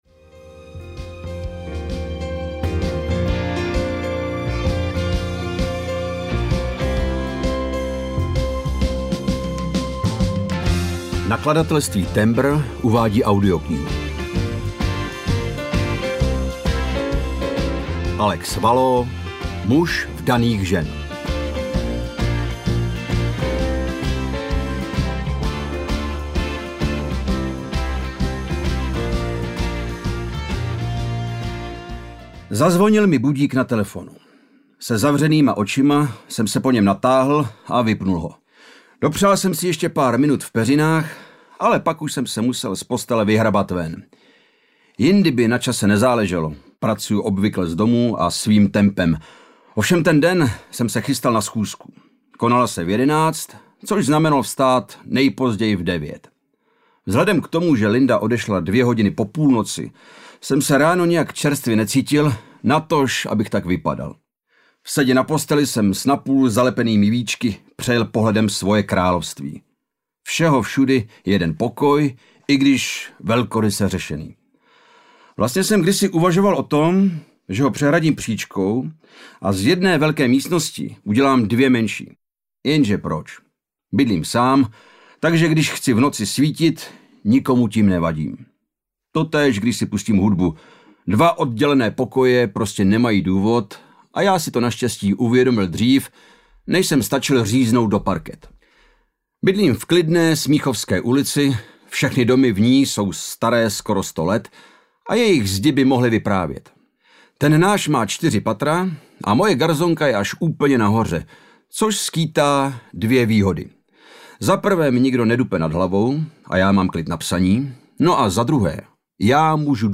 Muž vdaných žen audiokniha
Ukázka z knihy
muz-vdanych-zen-audiokniha